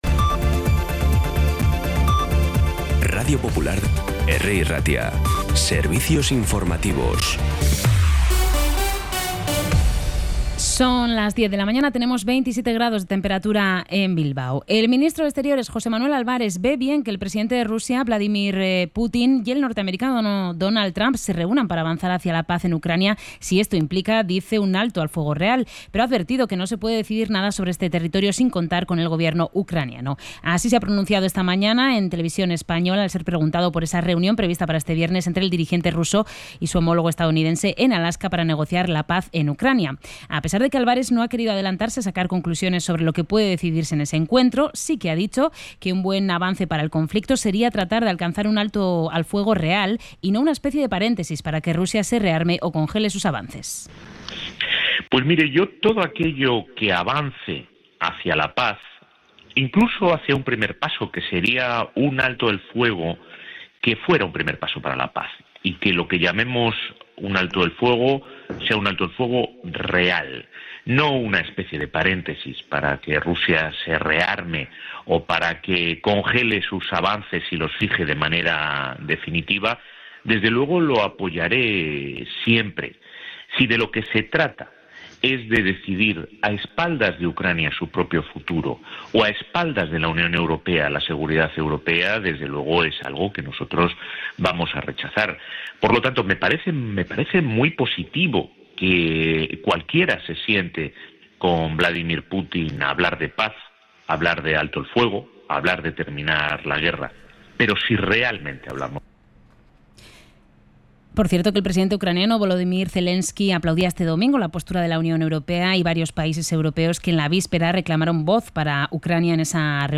La última hora más cercana, de proximidad, con los boletines informativos de Radio Popular.
Los titulares actualizados con las voces del día. Bilbao, Bizkaia, comarcas, política, sociedad, cultura, sucesos, información de servicio público.